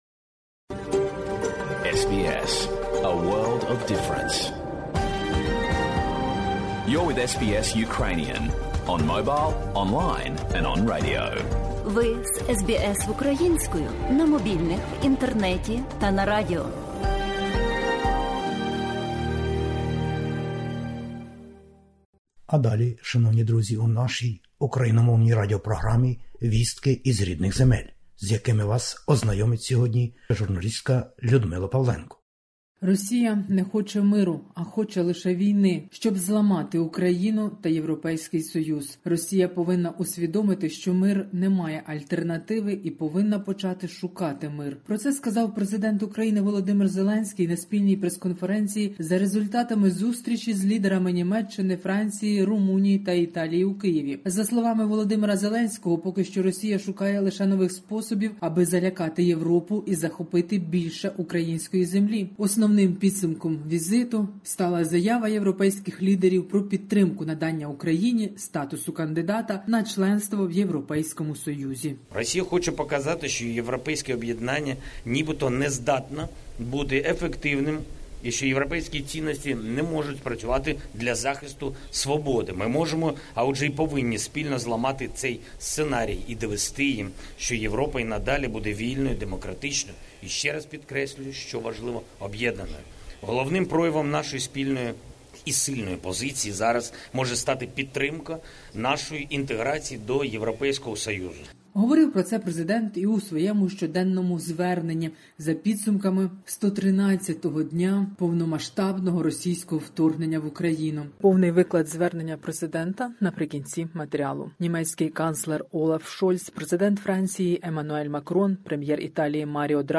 Добірка новин із героїчної України.